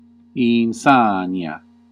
Ääntäminen
IPA : /ɪnˈsænɪti/